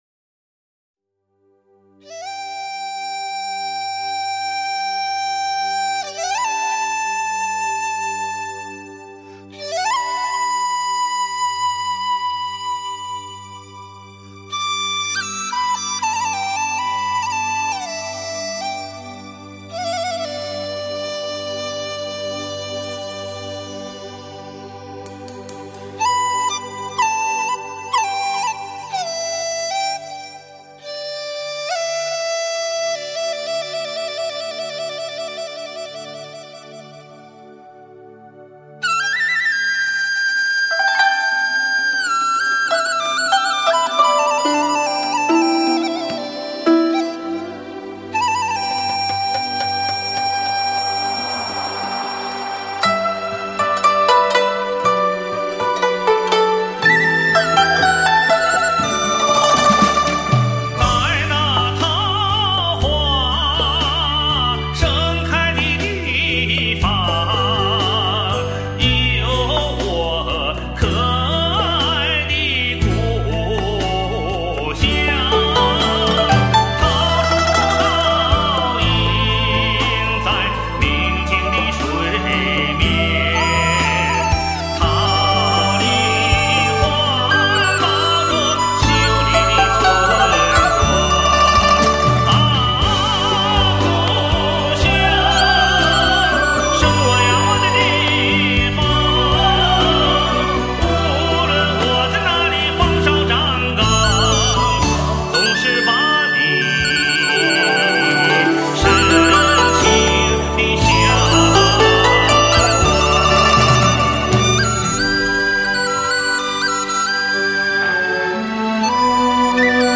按发烧级要求重新编配、真乐器伴奏